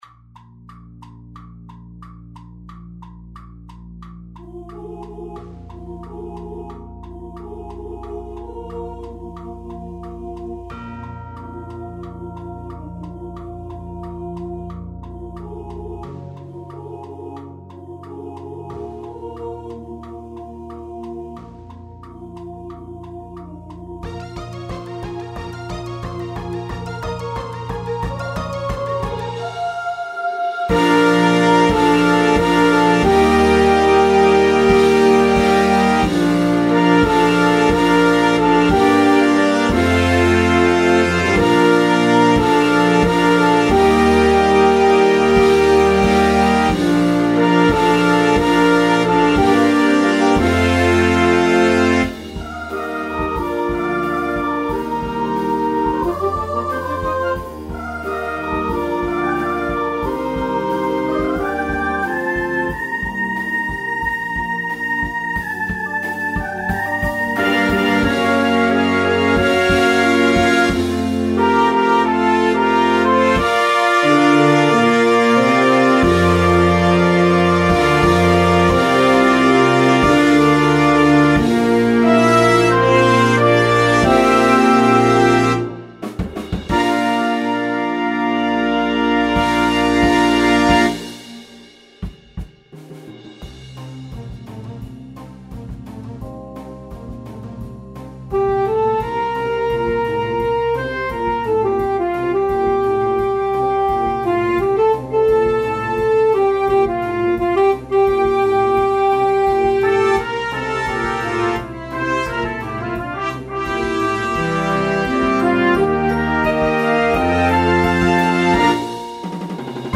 This show brings rhythm, nostalgia, and excitement